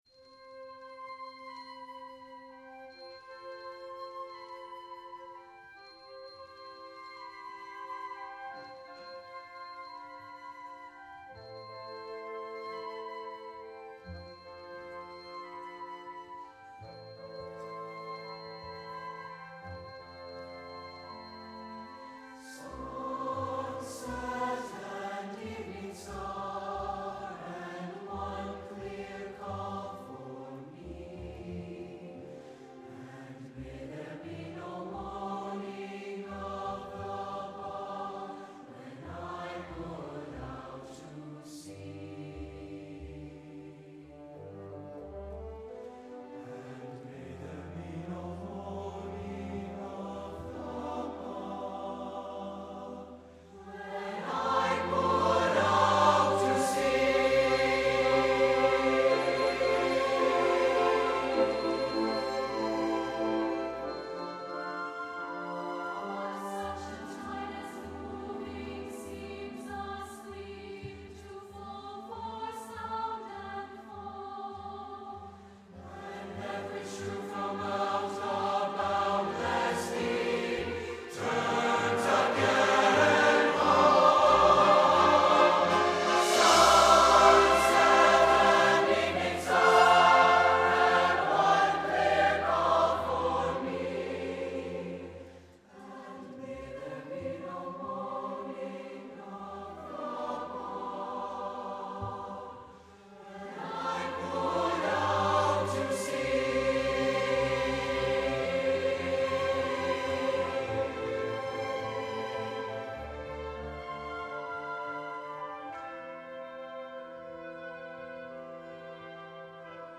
SATB and orchestra